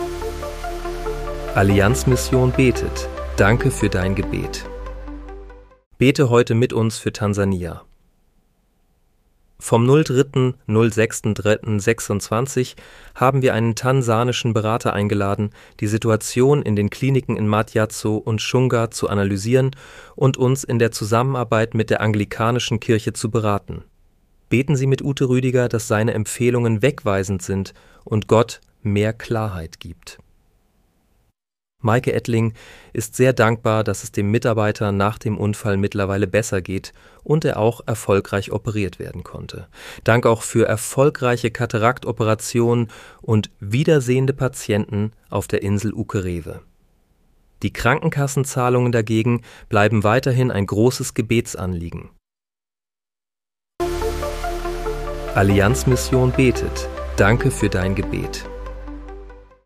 Bete am 03. März 2026 mit uns für Tansania. (KI-generiert mit der